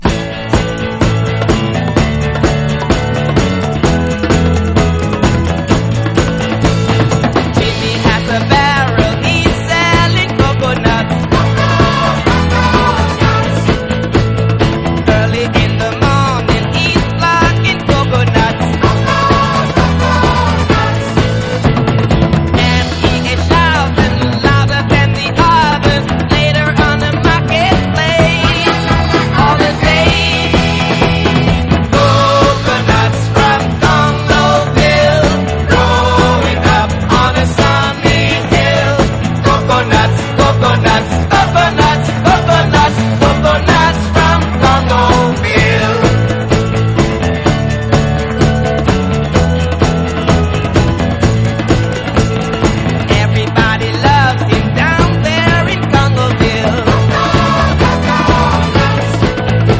WORLD / REGGAE / ROOTS / EARLY REGGAE / SKINHEAD REGGAE
ラベルに書き込み・ノイズ大